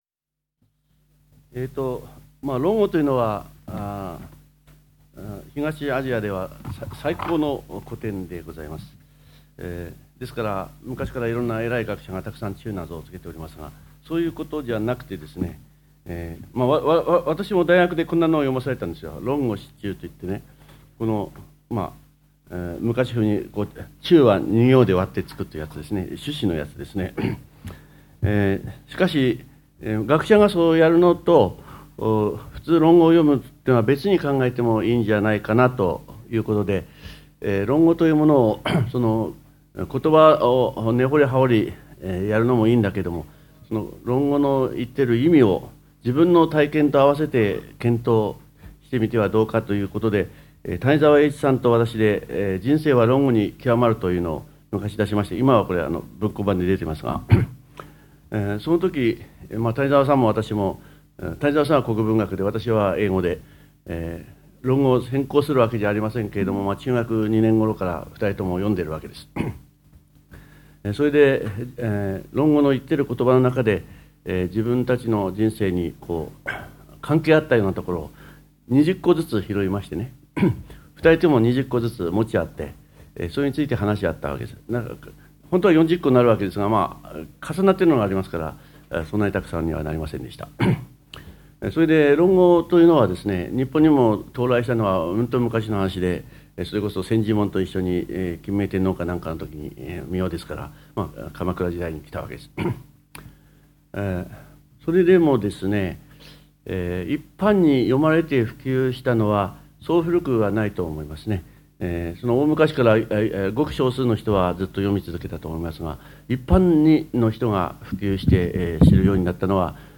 ※この音声は平成13年に開催した致知出版社主催の「歴史に学ぶ『修己治人』の成功学」での講演を収録したもので、「渡部昇一講演録ＣＤ修養2」第3巻で収録されているものと同じ内容です。